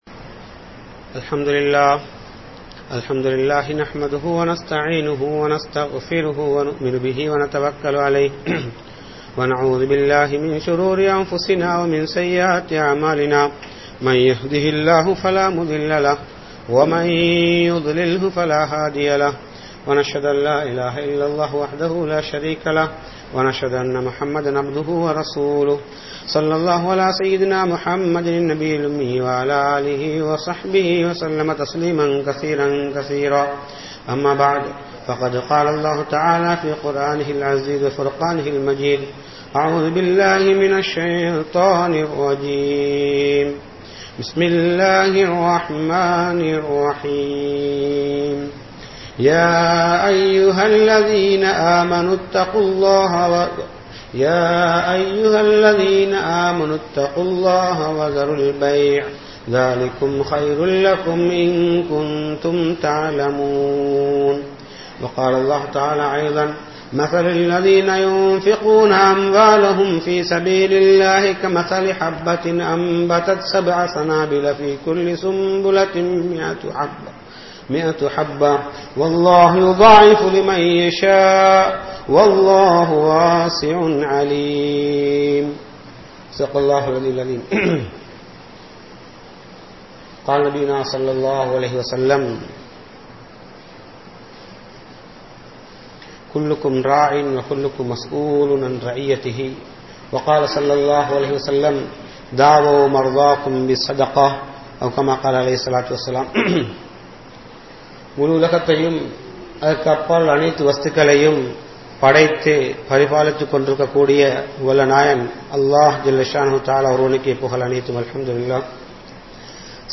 Vattien Mudivu Alivu Aahum (வட்டியின் முடிவு அழிவு ஆகும்) | Audio Bayans | All Ceylon Muslim Youth Community | Addalaichenai
Masjidhul Hakam Jumua Masjidh